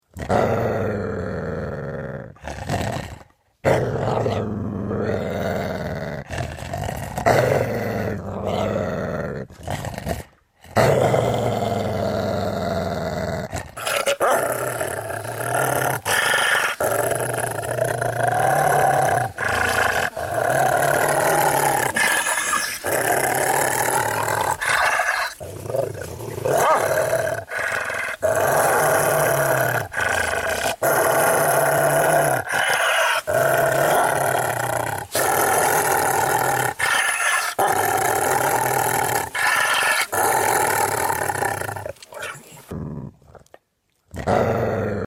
Tiếng Chó Gầm Gừ MP3